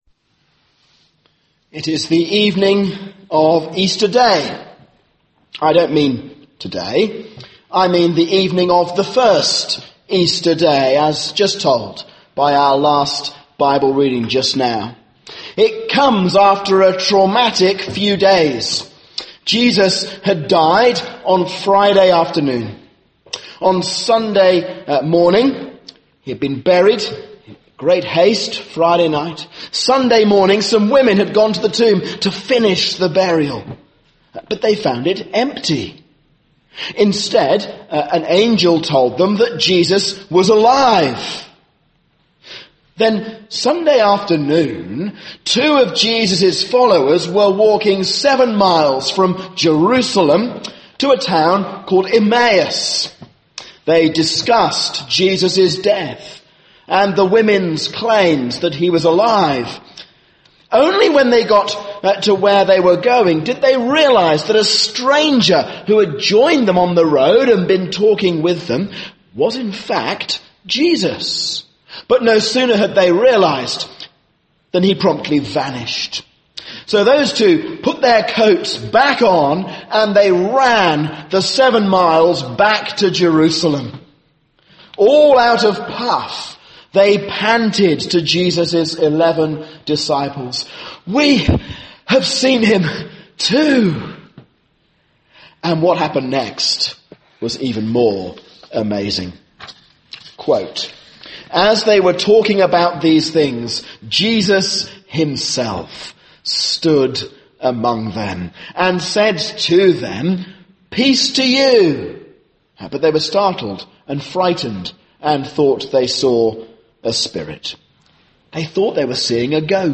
A sermon on Luke 24:36-53